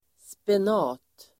Ladda ner uttalet
spenat.mp3